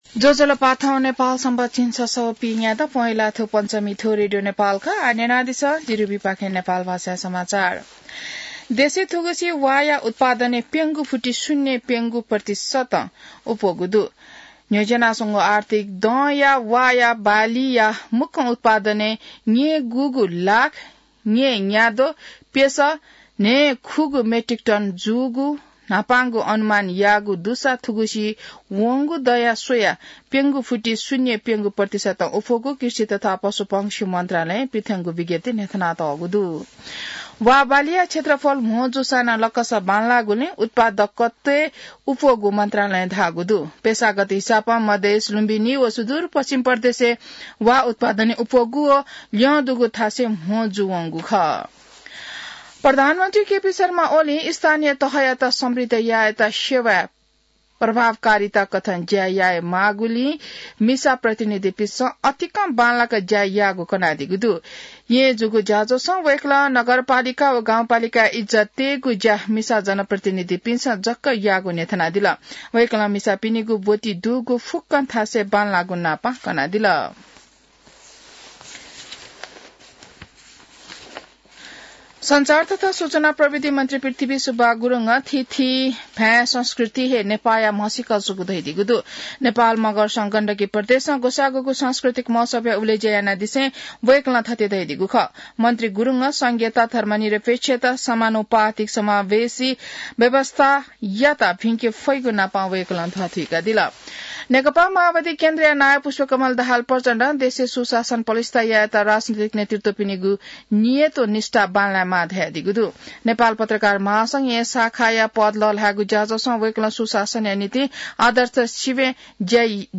नेपाल भाषामा समाचार : २१ पुष , २०८१